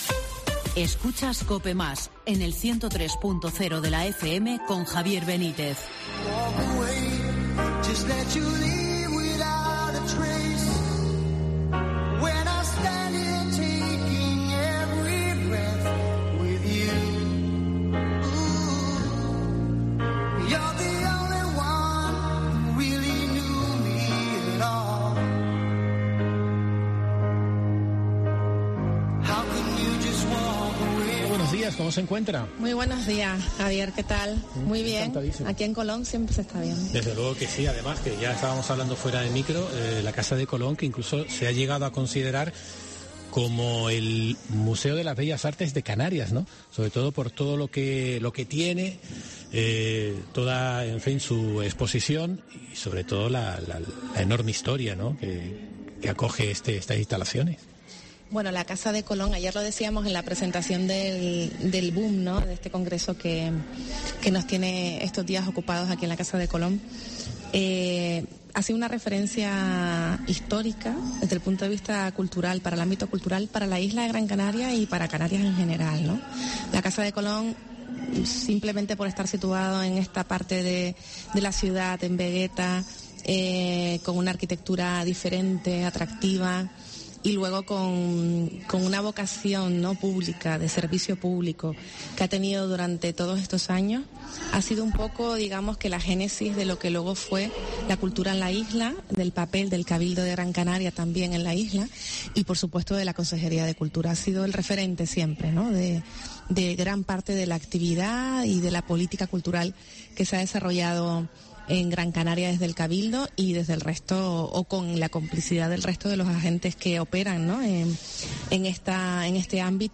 Entrevista Guacimara Medina, consejera de Cultura del Cabildo de Gran Canaria